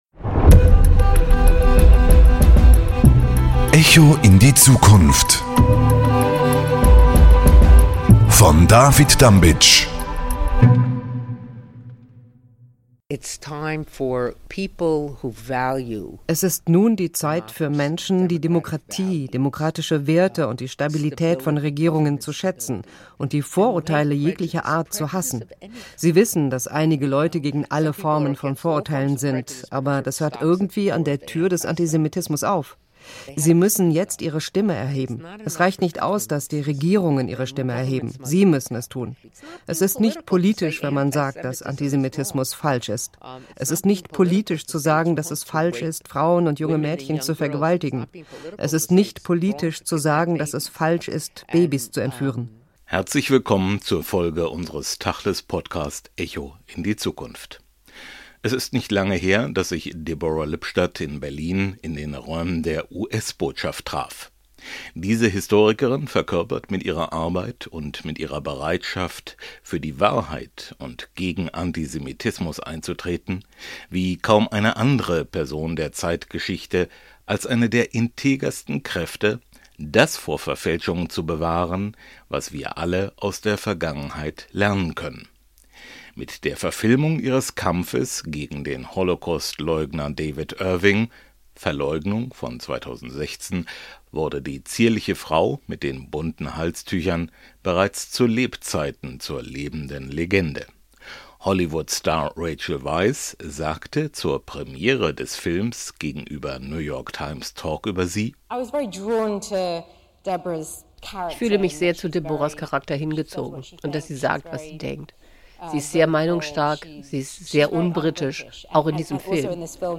spricht die Antisemitismusexpertin Deborah Lipstadt